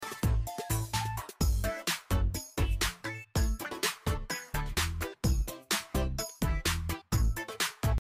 Monkey Vlogging in Park (Funny sound effects free download
You Just Search Sound Effects And Download. funny sound effects on tiktok Download Sound Effect Home